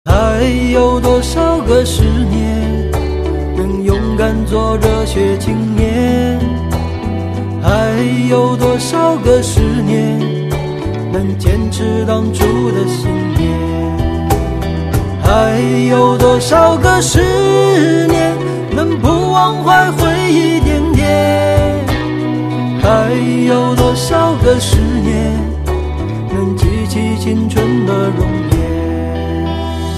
M4R铃声, MP3铃声, 华语歌曲 76 首发日期：2018-05-14 09:04 星期一